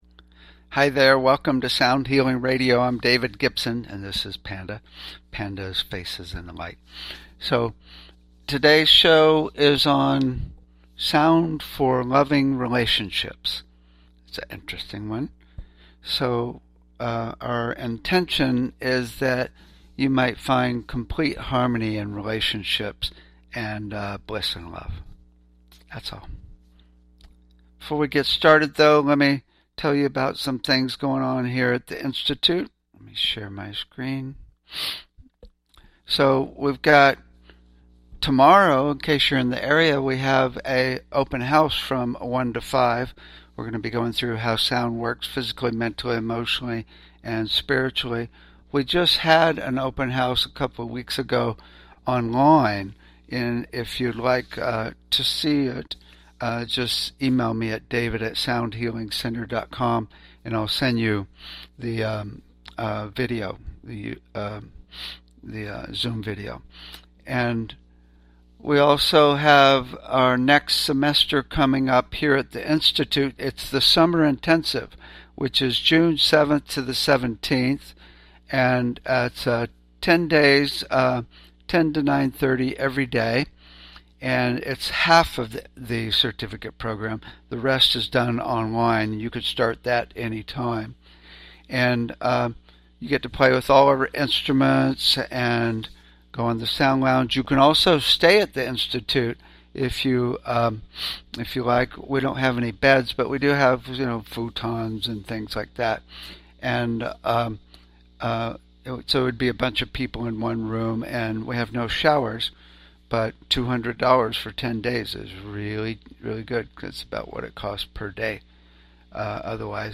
Talk Show Episode, Audio Podcast, Sound Healing and Sound For Loving Relationships on , show guests , about Sound For Loving Relationships, categorized as Education,Health & Lifestyle,Sound Healing,History,Physics & Metaphysics,Science,Self Help,Society and Culture,Spiritual